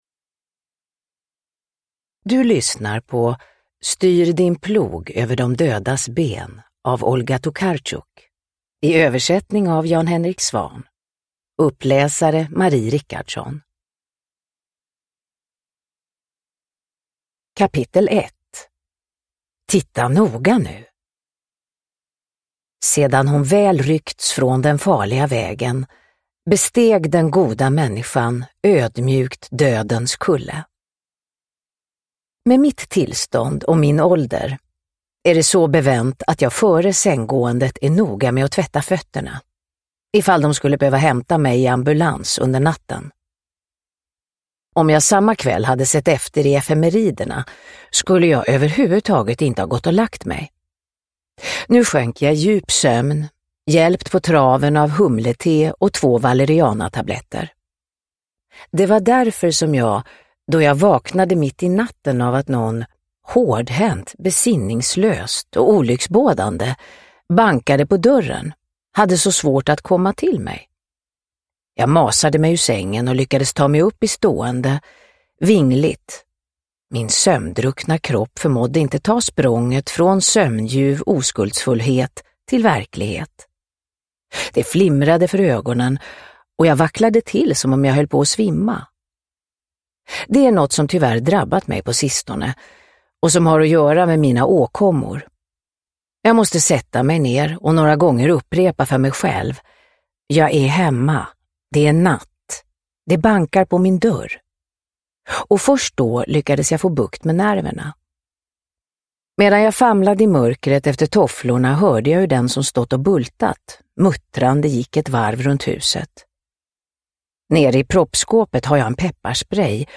Styr din plog över de dödas ben – Ljudbok – Laddas ner
Uppläsare: Marie Richardson